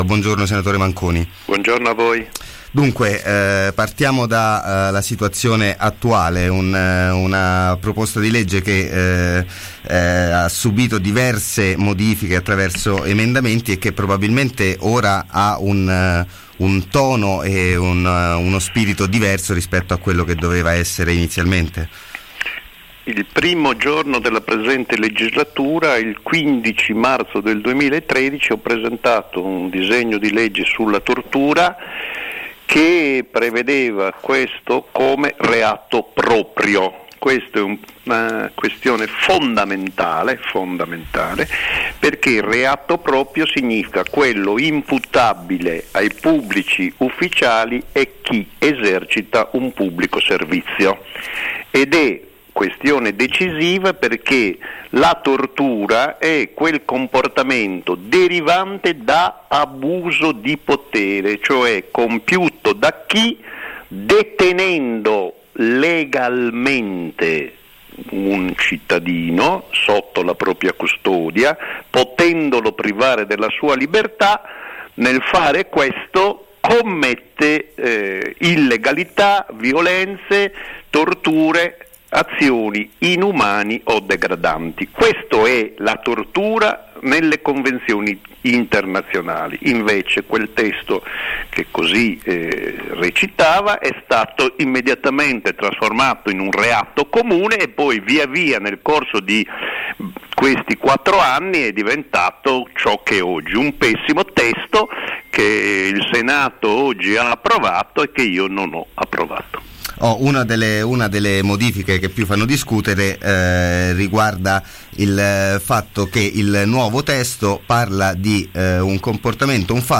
Legge sul reato di tortura, intervista al Senatore Luigi Manconi